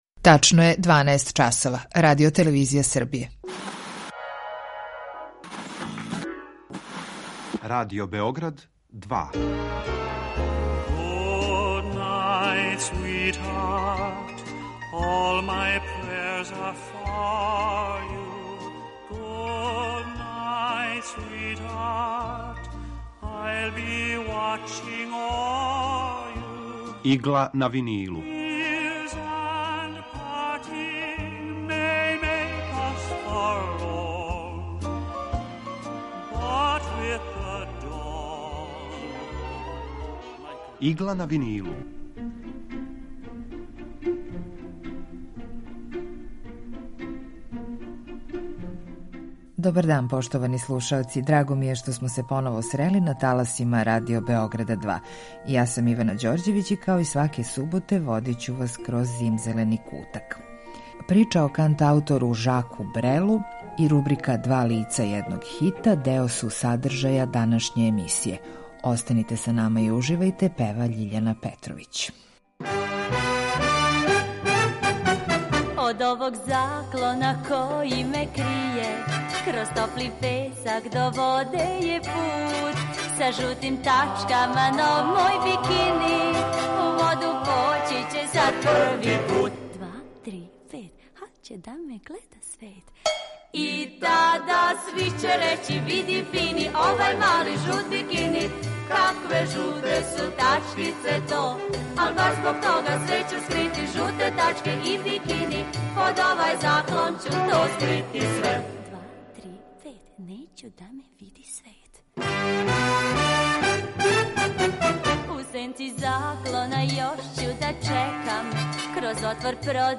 Евергрин музика